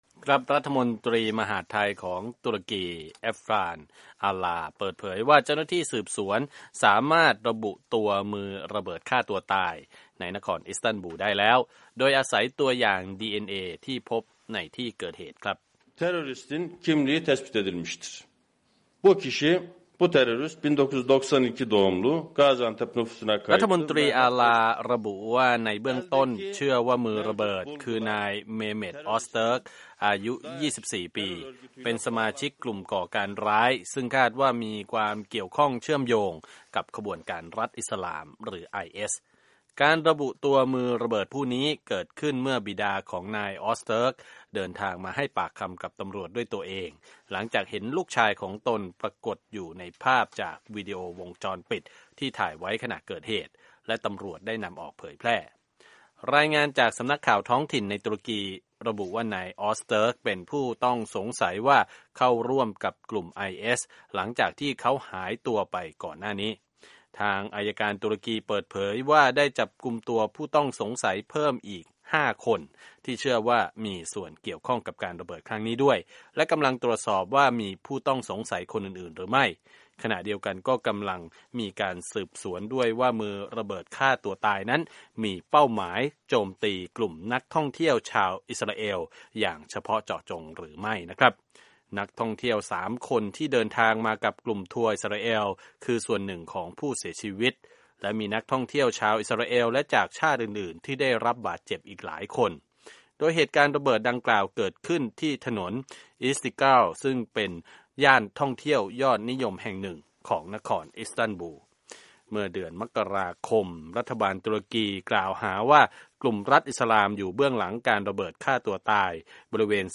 รายงานจากนครอิสตันบุล